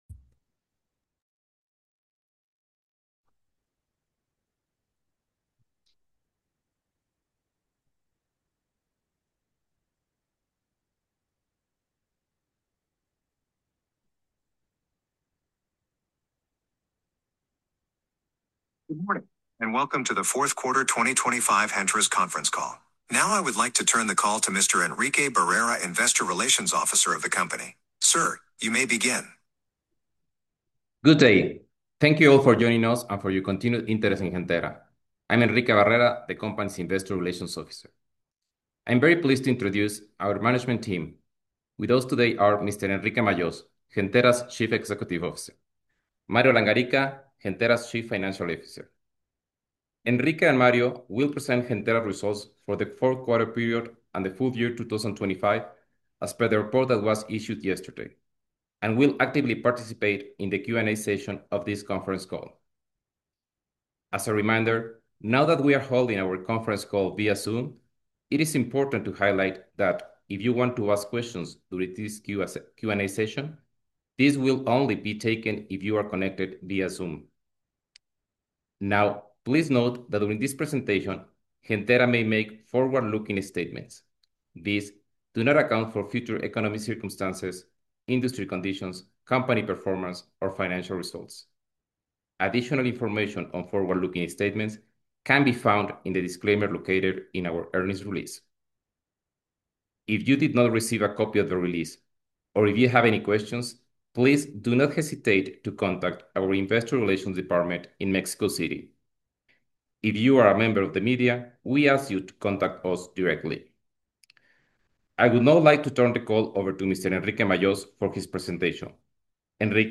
Conference call